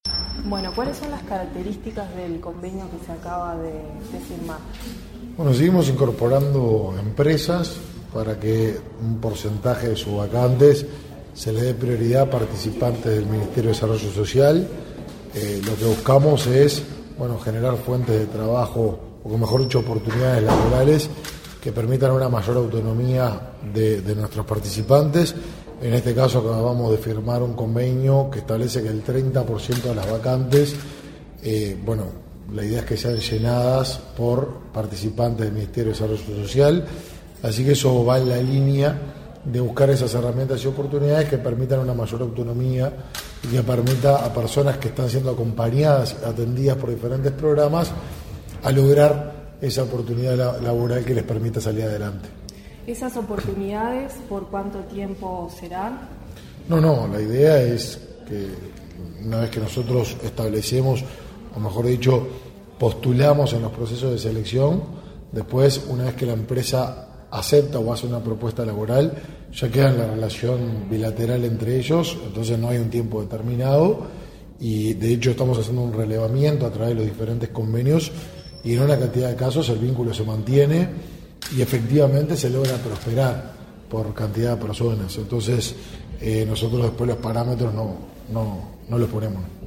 Entrevista al ministro de Desarrollo Social, Martín Lema | Presidencia Uruguay